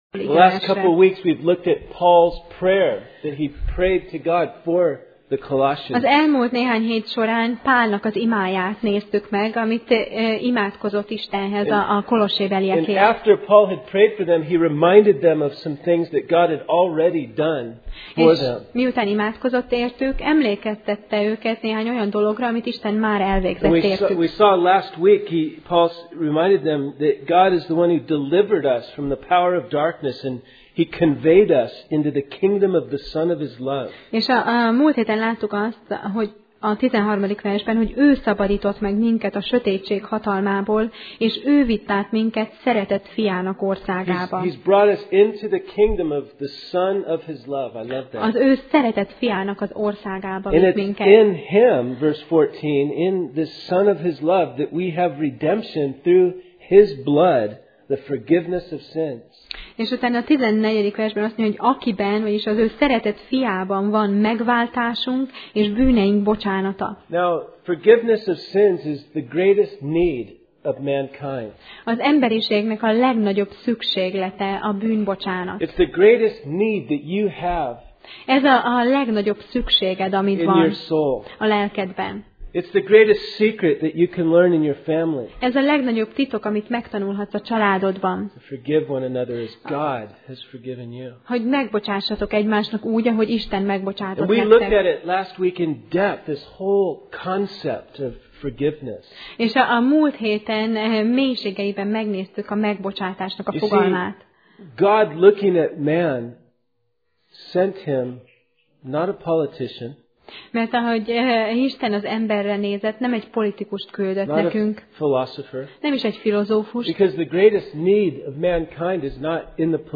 Kolossé Passage: Kolossé (Colossians) 1:15-17 Alkalom: Vasárnap Reggel